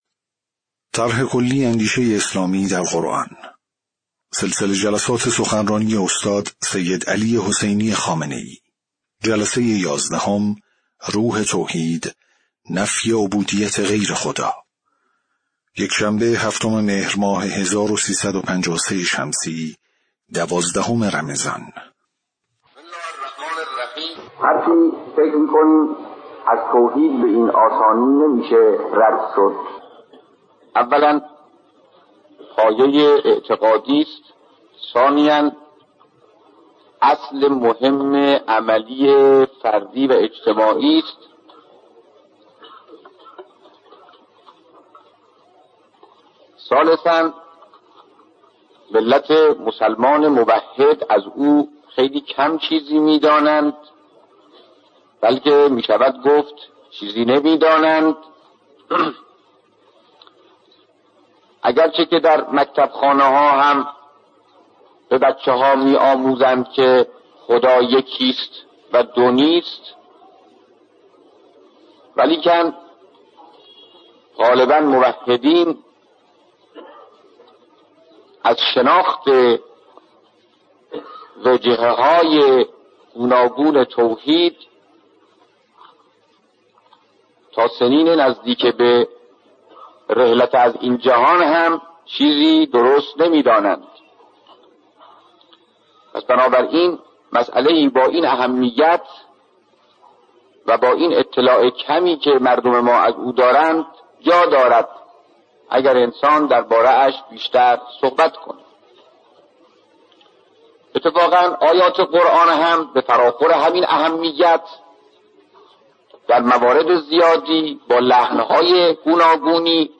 صوت/ جلسه‌ یازدهم سخنرانی استاد سیدعلی‌ خامنه‌ای رمضان۱۳۵۳